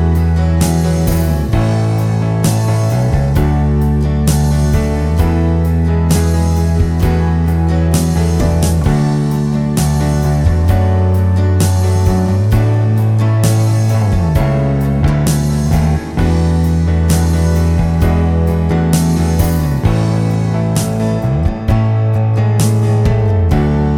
No Backing Vocals Soundtracks 3:42 Buy £1.50